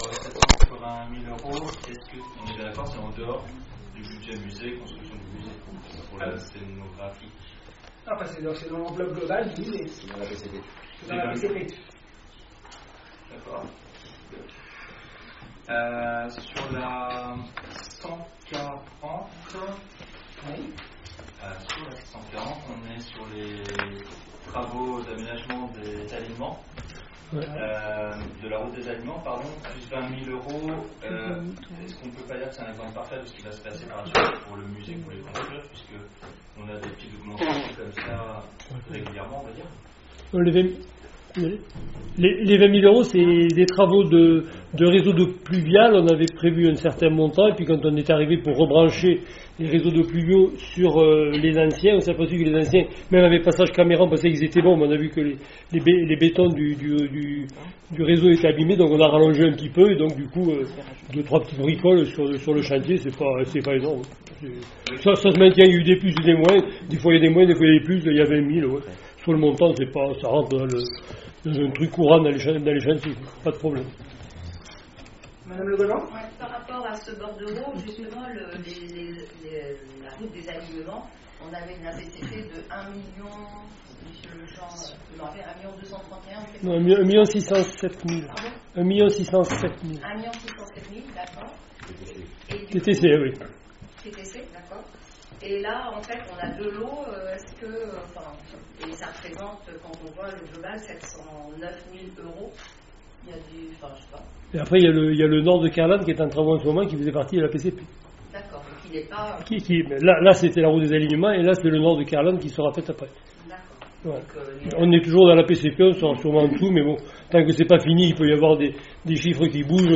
Conseil Municipal du 23 octobre 2025 - Enregistrement de la séance - Carnac